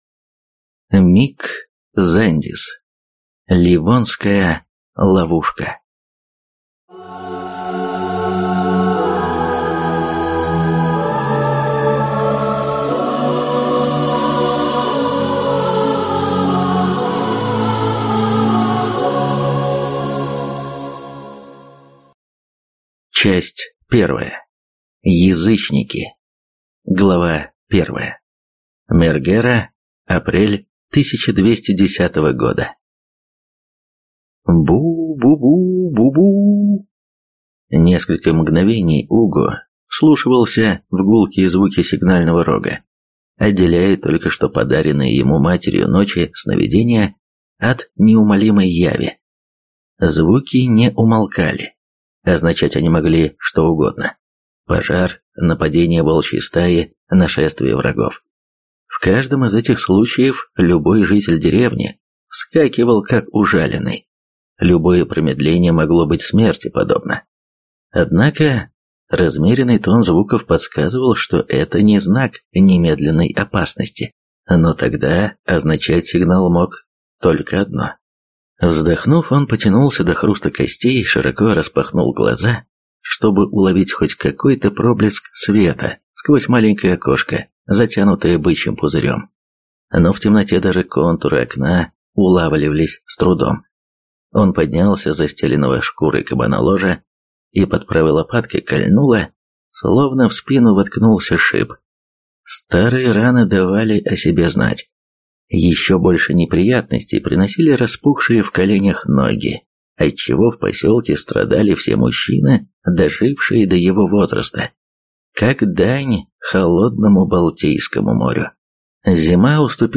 Аудиокнига Ливонская ловушка | Библиотека аудиокниг